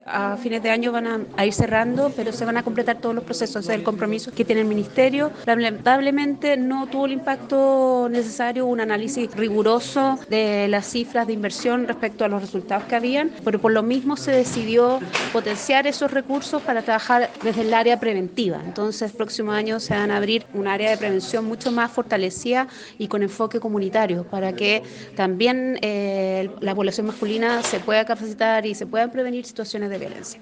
La seremi de la Mujer y Equidad de Género en Los Ríos, Francisca Corbalán, confirmó que a fines de este año se concretará su cierre.